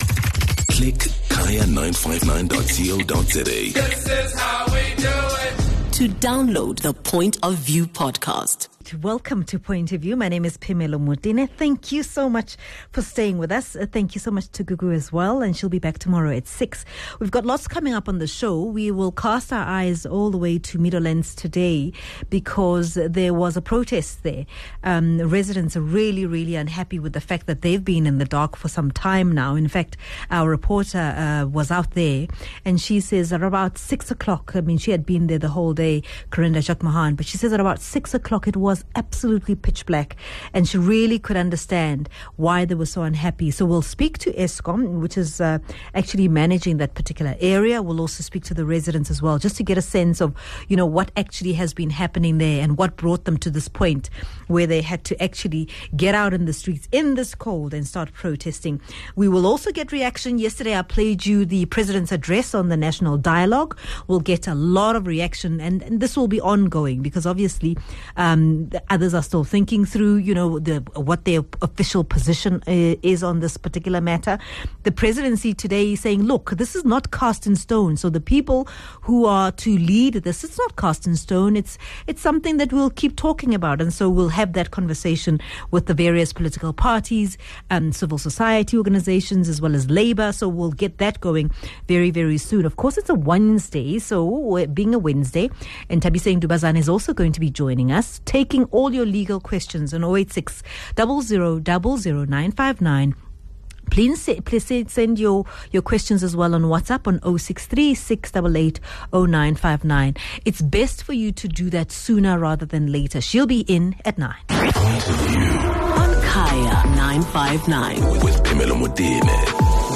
speaks to OR Tambo District Municipality Mayor, Mesuli Ngqondwana.